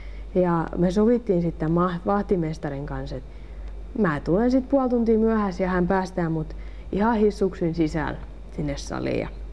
c) Lounaismurre: